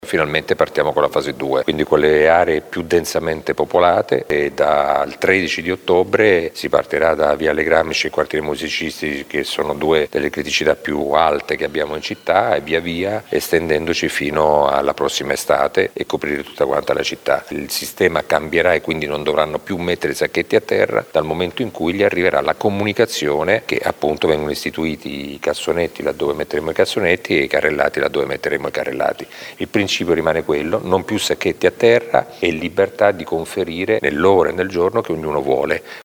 Le parole del sindaco Massimo Mezzetti